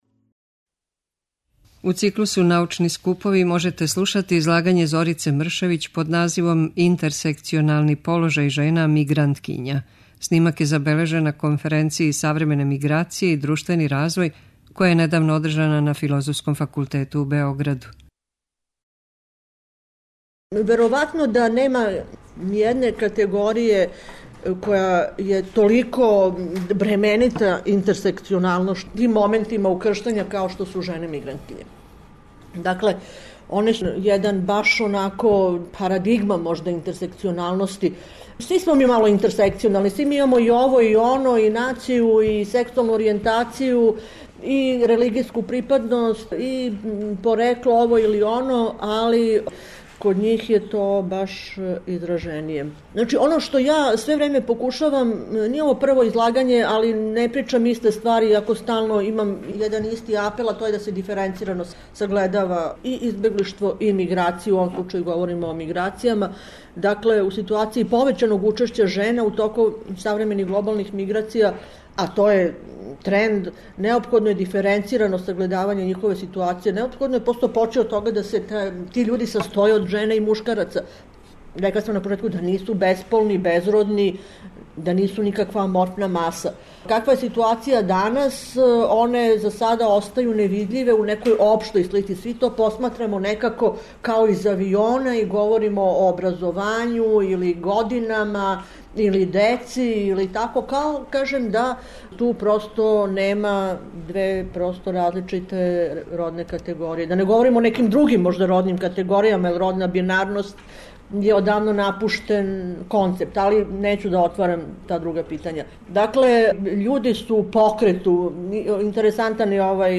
Научни скупови